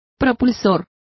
Complete with pronunciation of the translation of propeller.